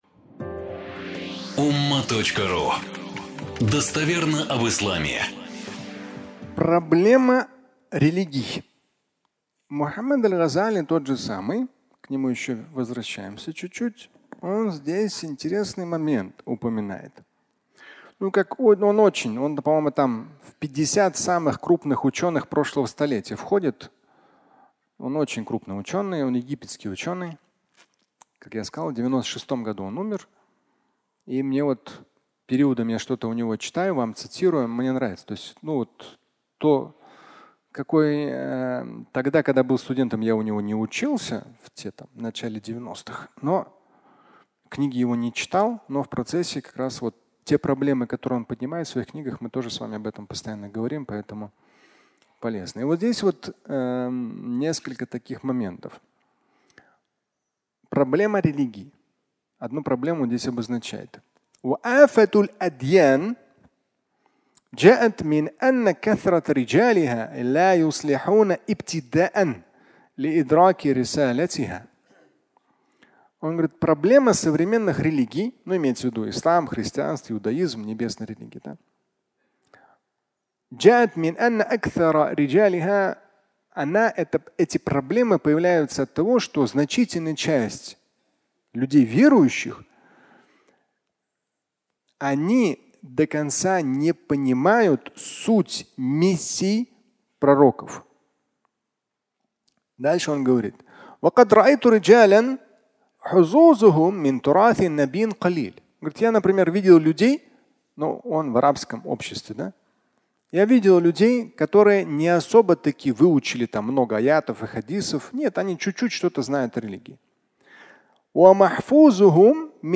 Проблема религий (аудиолекция)